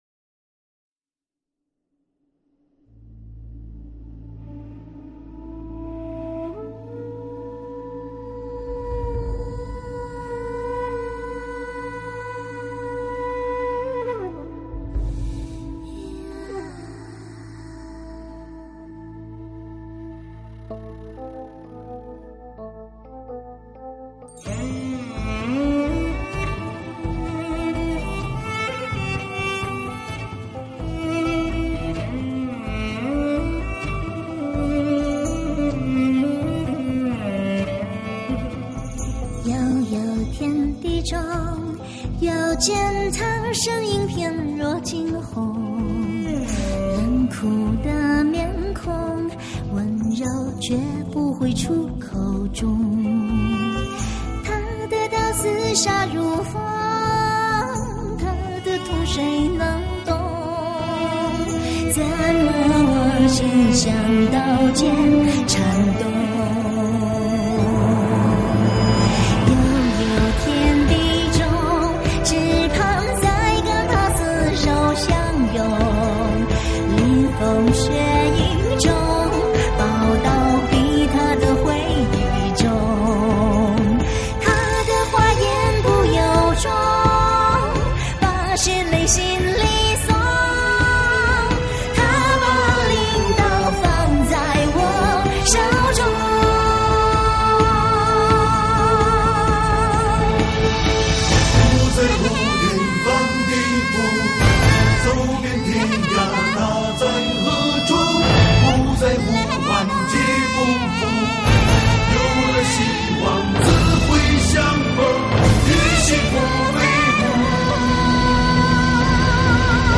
这首同名主题歌的风格是她最擅长的世界音乐与中国风的混搭，
借用民乐、弦乐与电子乐的糅合，与电影本身的气质不谋而合。
讲述她对爱人青龙的复杂心情，是一首催人泪下的情歌，